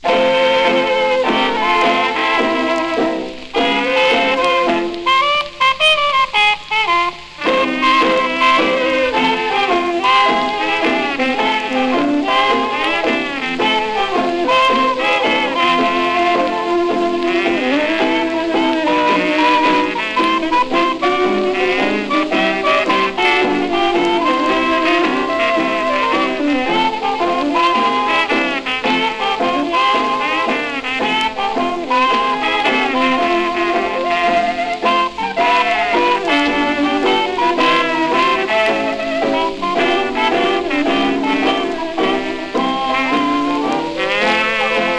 Jazz, Ragtime, New Orleans　USA　12inchレコード　33rpm　Mono